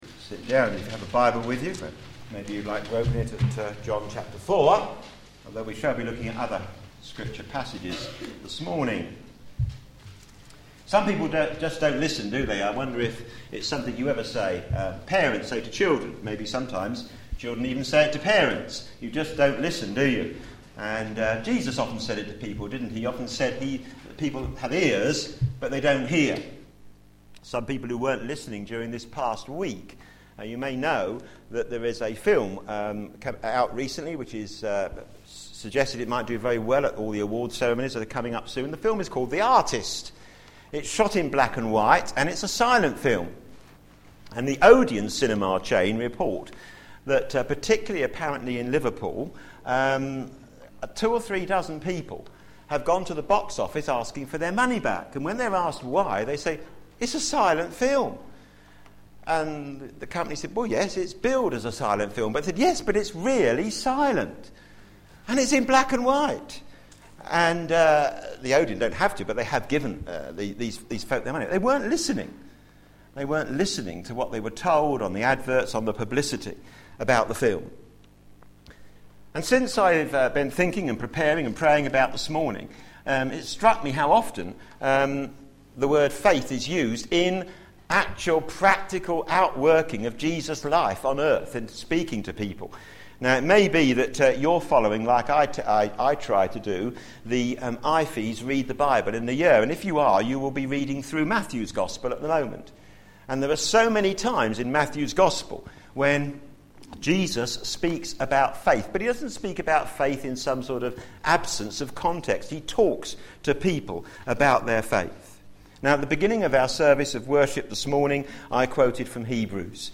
John on Jesus Theme: What is real faith? Sermon